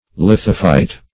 Lithophyte \Lith"o*phyte\, n. [Litho- + Gr.